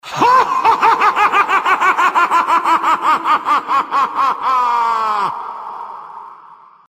Risada Diabólica Do Capiroto
Risada diabólica ou demoníaca do diabo/Satanás/capiroto. Riso malvado. Risada maligna ou perversa.
risada-diabolica-caipiroto.mp3